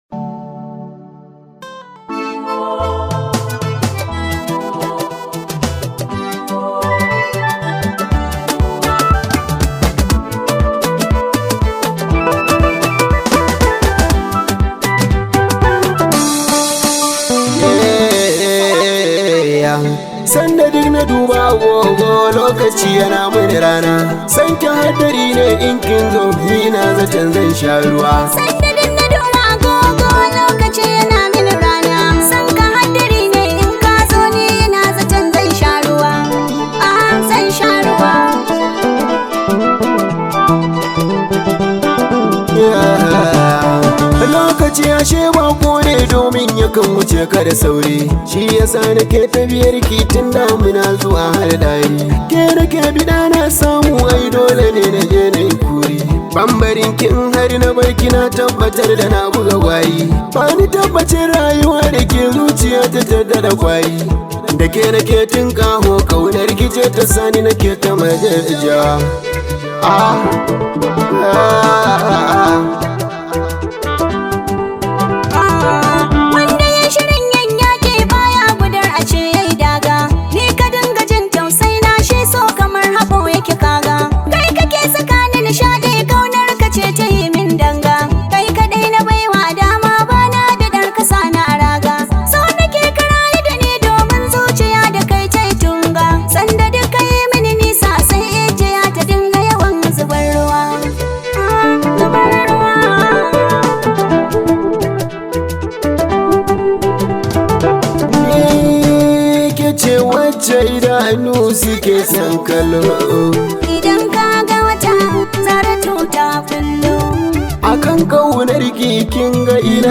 much appreciated hausa song known as
top rated Nigerian Hausa Music artist
This high vibe hausa song